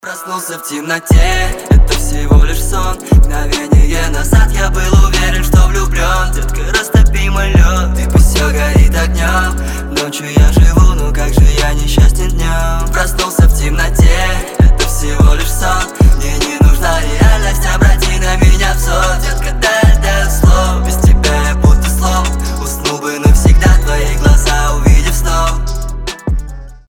мужской вокал
лирика
русский рэп